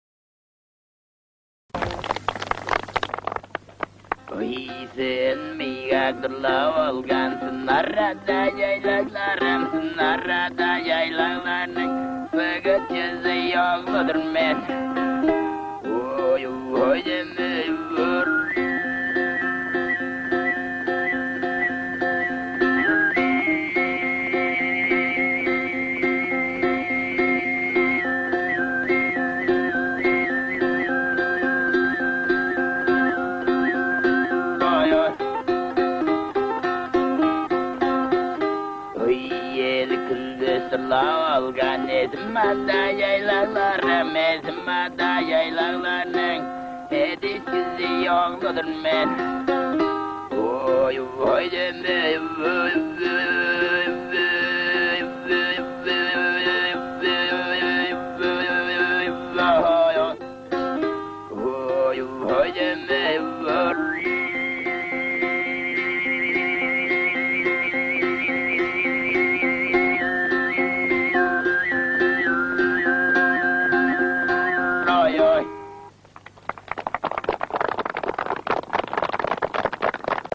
Listen to Tuvan Overtone Singing
Mongolian Tuva.mp3